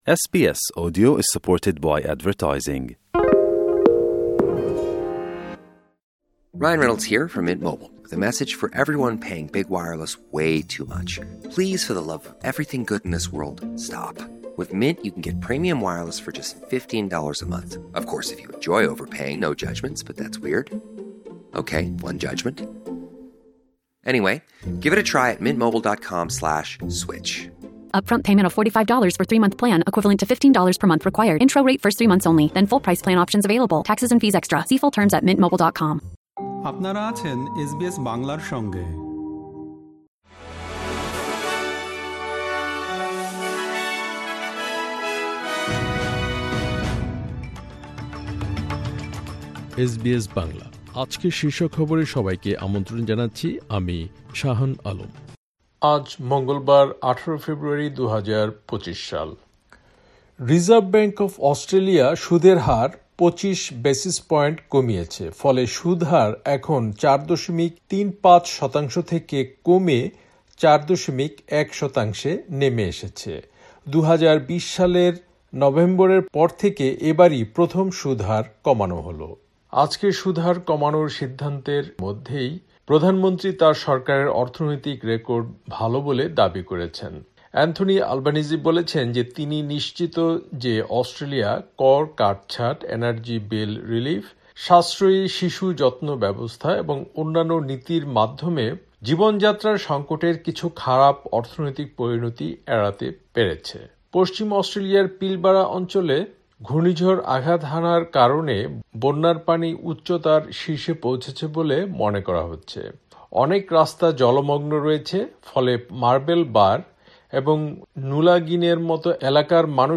অস্ট্রেলিয়ার জাতীয় ও আন্তর্জাতিক সংবাদের জন্য আজকের এসবিএস বাংলা শীর্ষ খবর শুনতে উপরের অডিও-প্লেয়ারটিতে ক্লিক করুন।